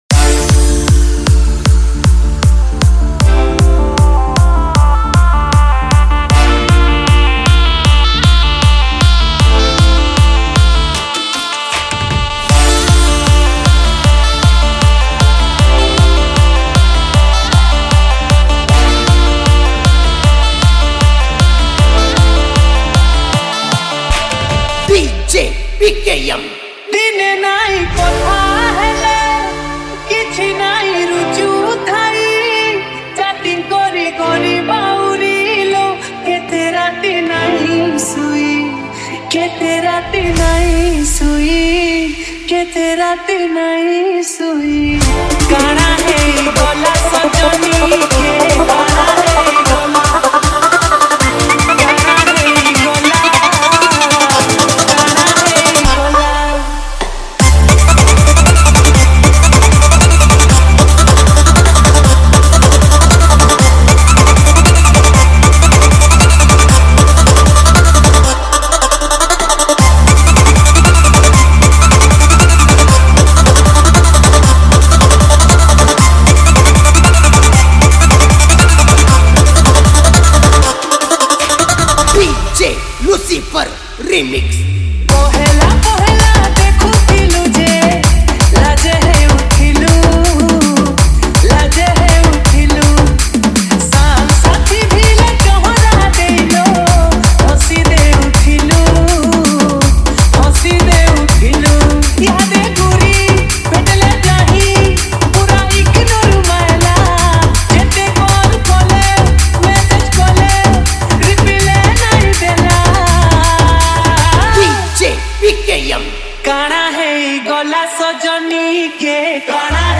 Single Dj Song Collection 2025